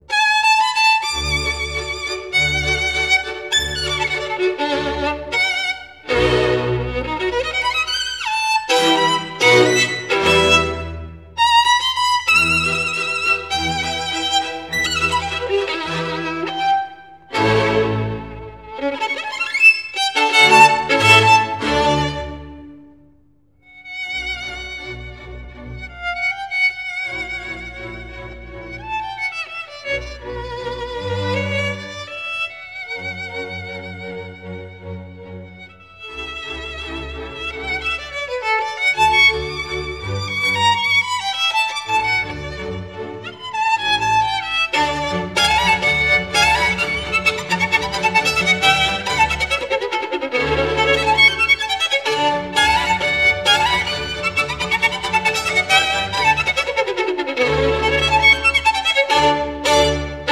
I also worked for some time on the filter for the violin music.
Processed
It does sound more open to me as well.